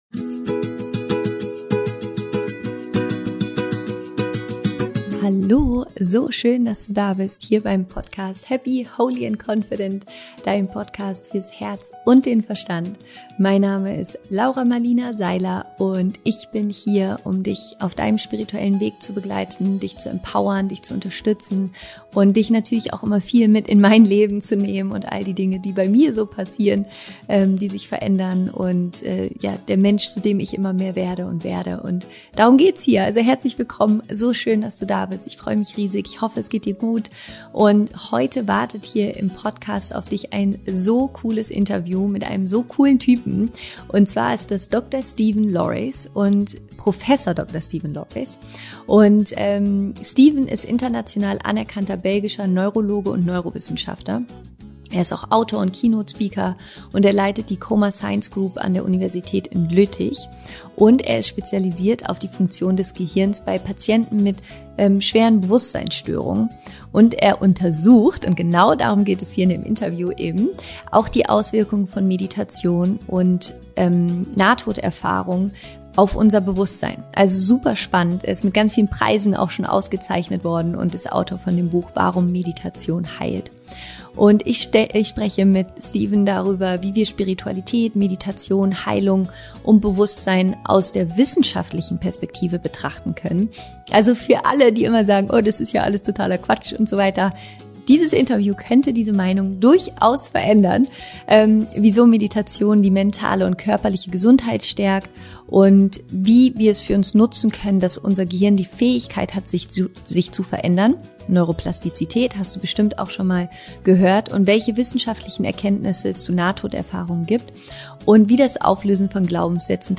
Welche Auswirkungen hat Meditation auf dein Gehirn? Dr. Steven Laureys im Interview